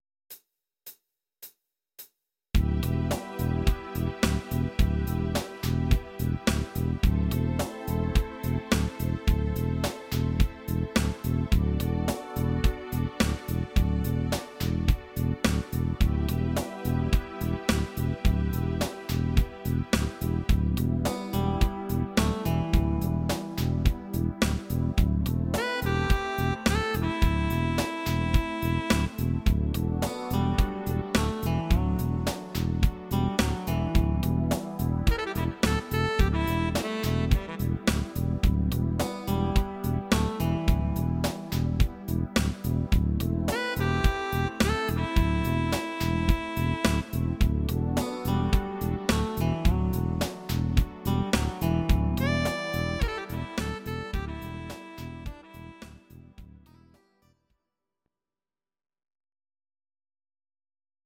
Saxophon